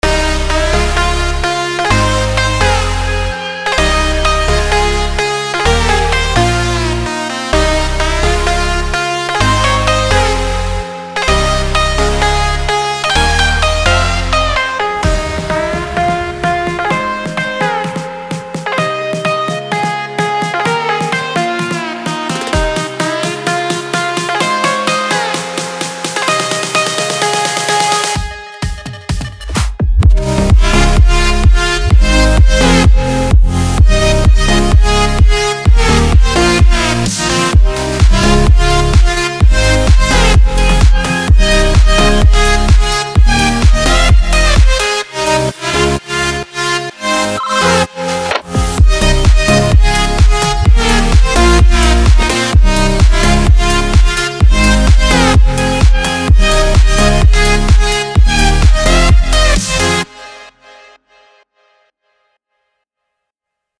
טראק בסגנון טכנו
TECHNO.mp3